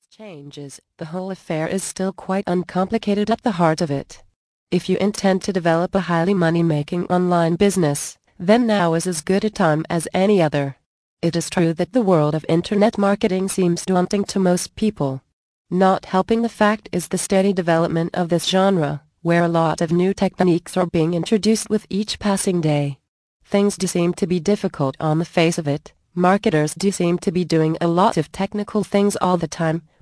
Indispensable Almanac of Internet Marketing mp3 Audio Book 1